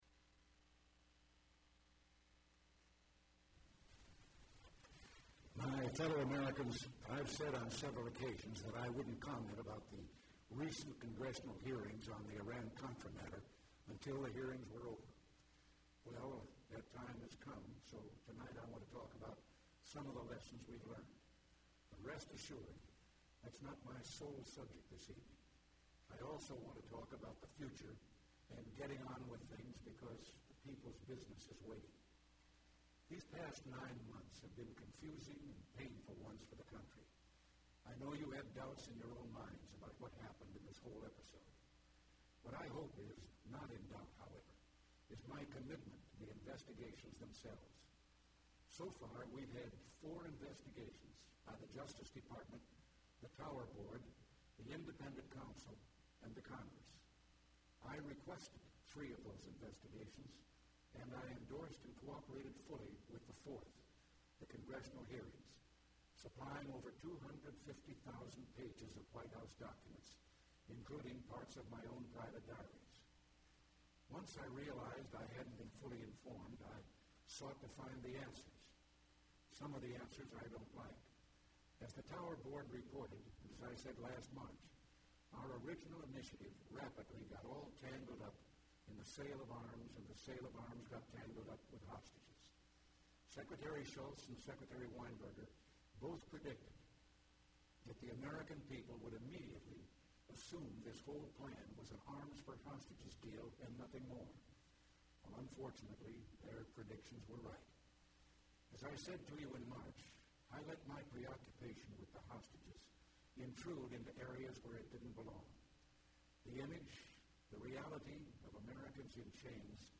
Remarks of the President during Address To The Nation Concerning Iran-Contra Aid Hearings